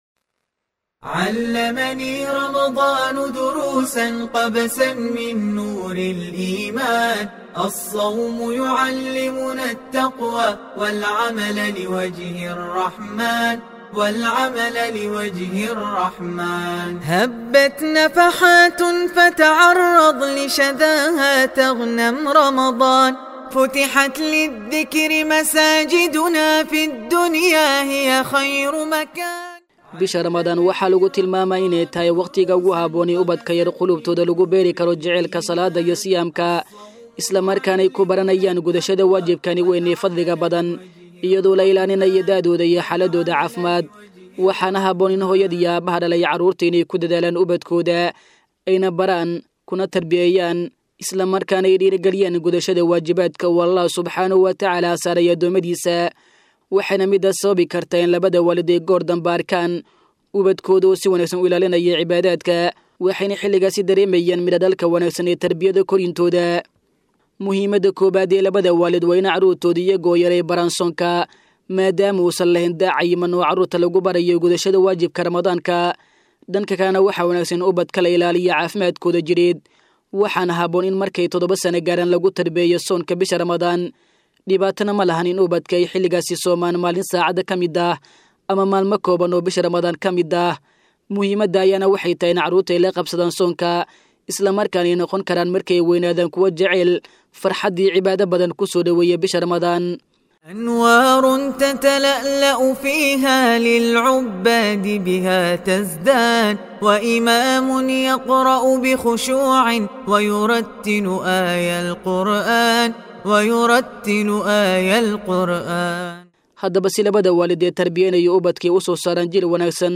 Warbixin: Sidee Caruurta u Jecleysiin Karnaa iney Soomaan Bisha Ramadaan?